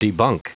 Prononciation du mot debunk en anglais (fichier audio)